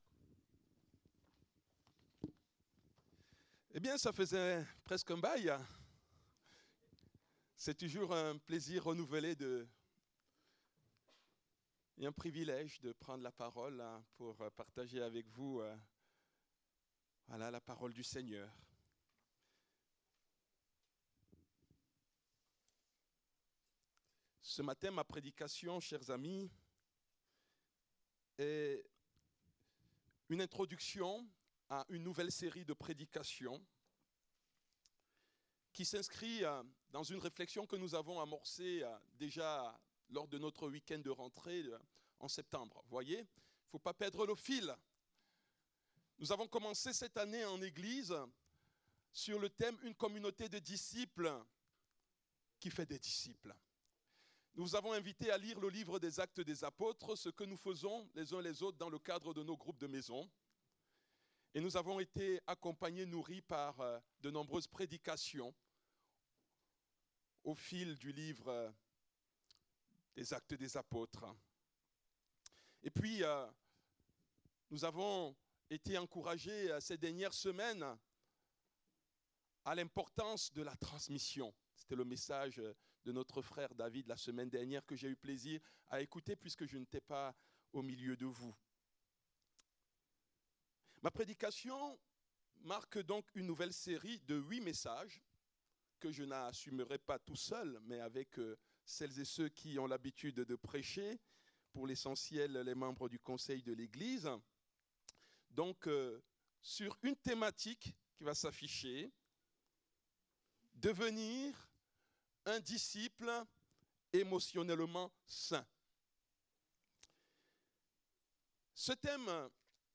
Culte du dimanche 02 février 2025, prédication apportée par le pasteur